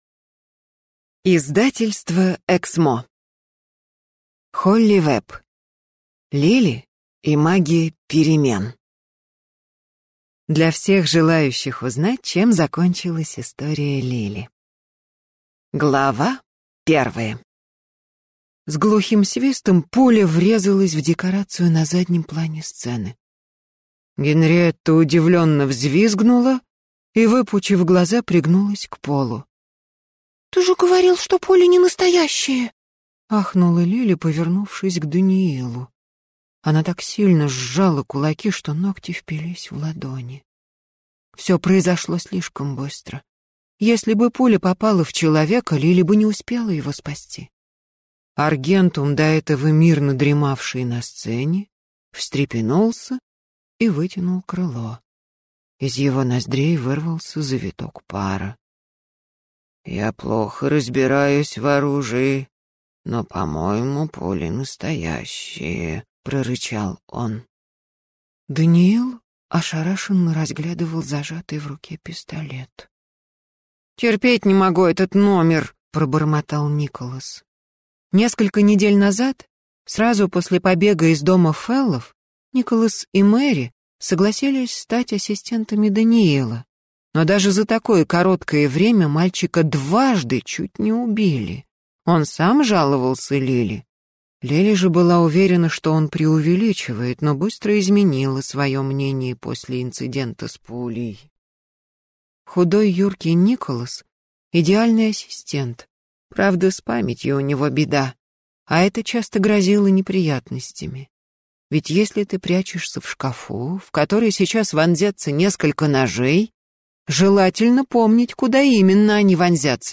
Аудиокнига Лили и магия перемен | Библиотека аудиокниг